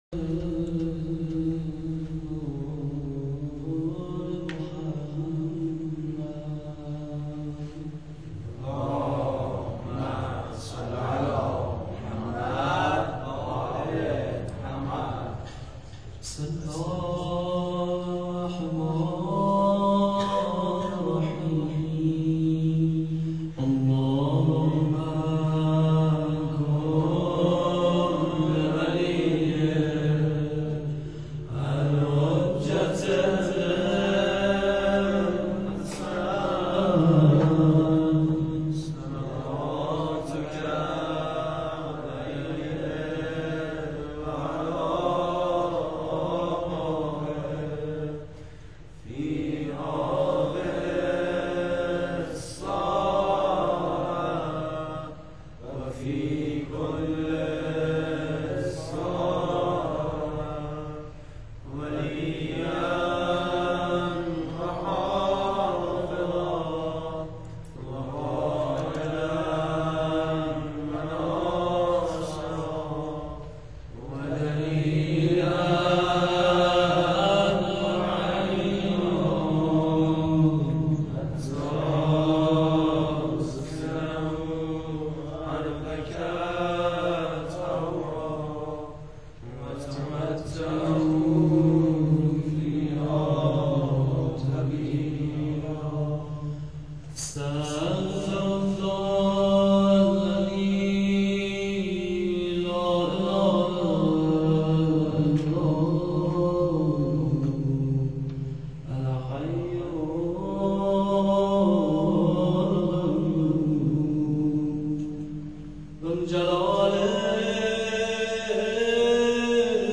روضه1.wma